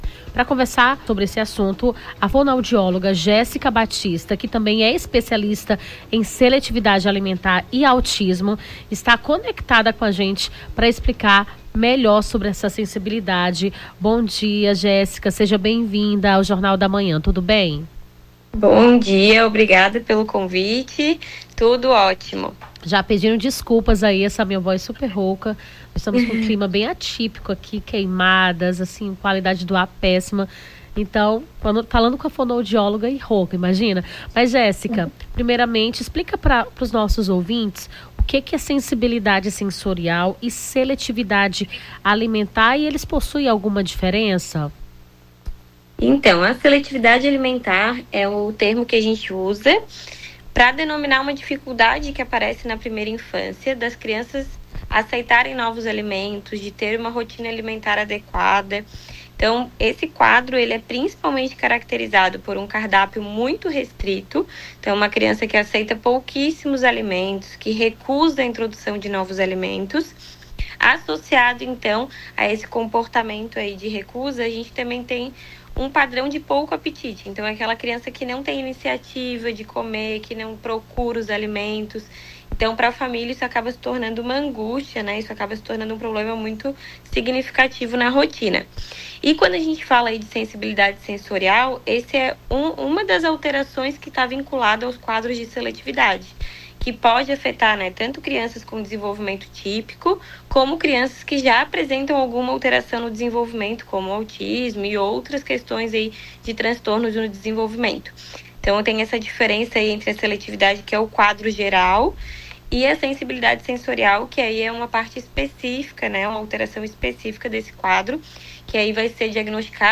Nome do Artista - CENSURA - ENTREVISTA (SENSIBILIDADE SENSORIAL) 31-07-24.mp3